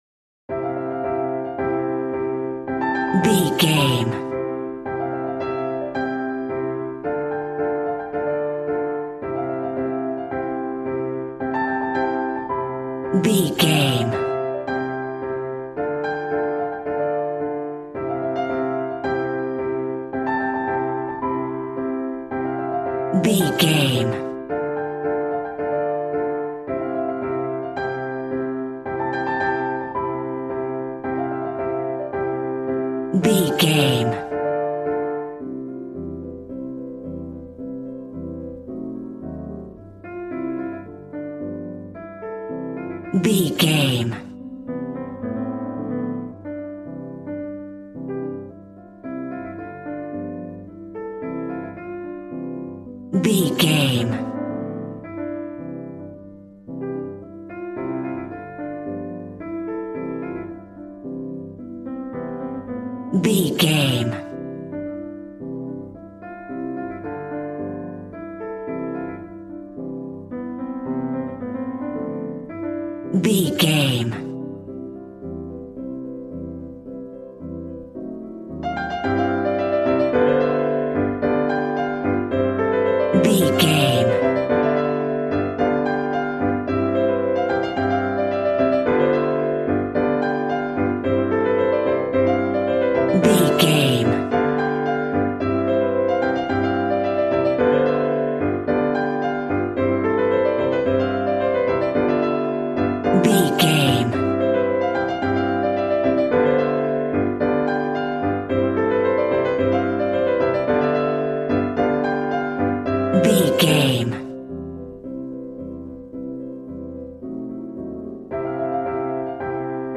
Ionian/Major
passionate
acoustic guitar